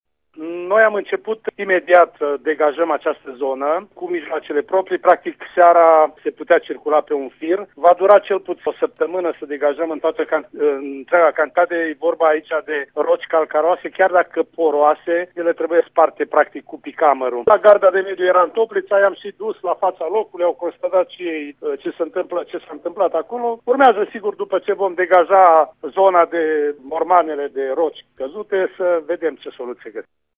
Stelu Platon spune că, în prezent, se lucrează la degajarea bucăţilor desprinse, după care se vor căuta soluţii pentru consolidarea versantului.